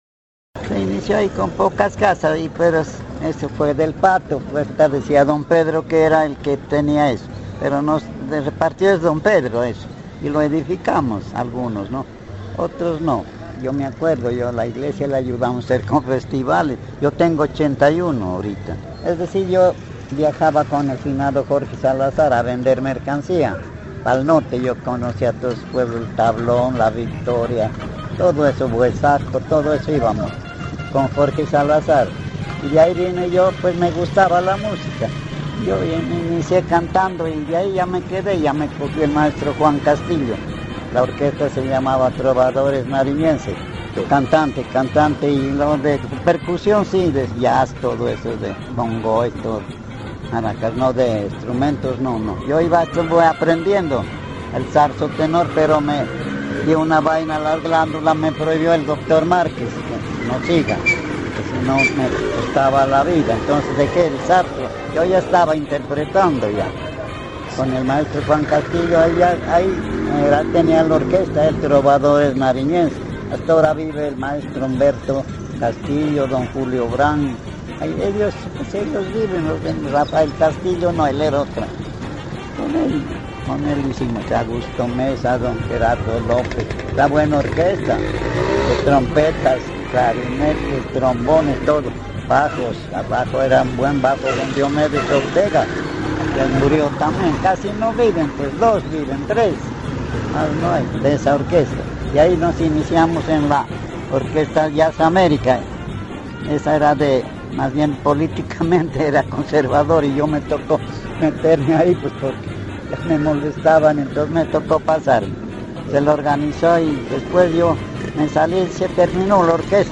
Entrevista realizada en junio de 2.012, en la cual se refiere al barrio Veinte de Julio, a la música, a algunos personajes de Sandoná y a su experiencia de niño, cuando asistió al Palacio Municipal a mirar la película “Blanca Nieves y los Siete Enanos”.